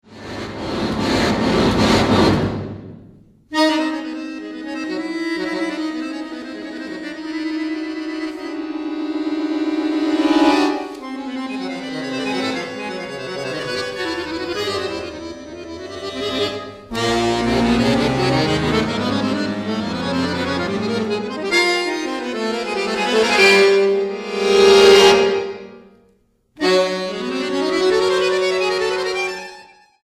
acordeonista
actrice y cantante